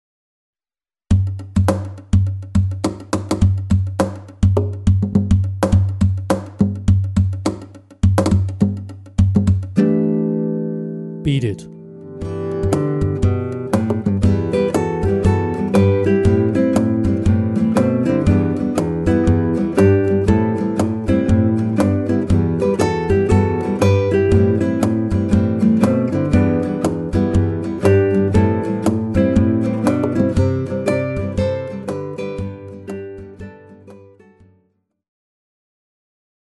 Guitar Percussion & Groove Ensemble mit CD/CD-ROM